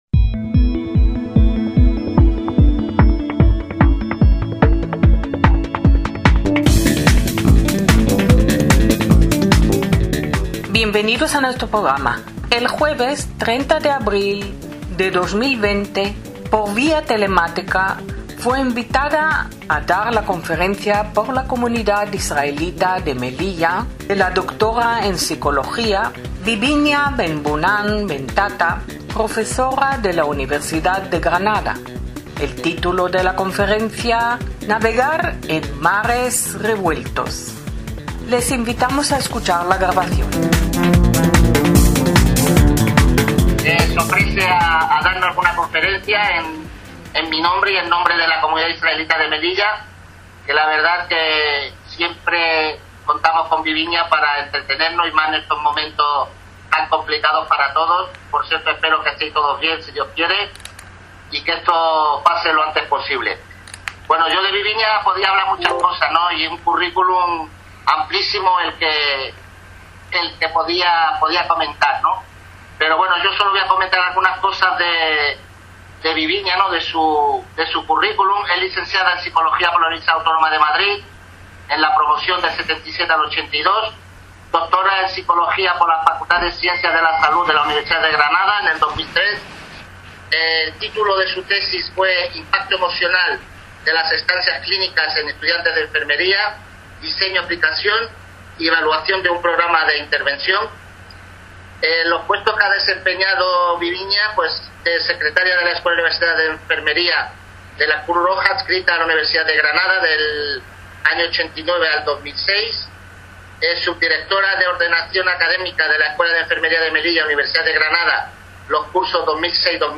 ACTOS "EN DIRECTO"